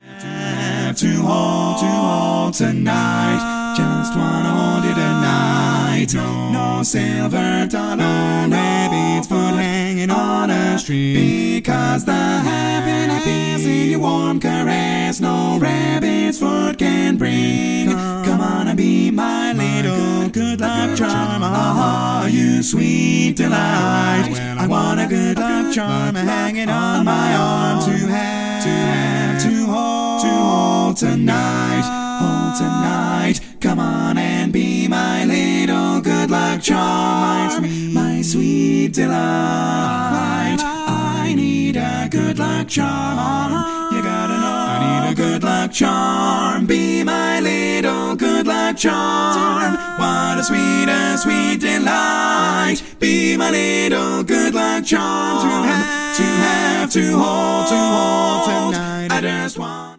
Full mix
Male